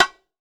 Index of /90_sSampleCDs/AKAI S6000 CD-ROM - Volume 5/Cuba2/STEREO_BONGO_2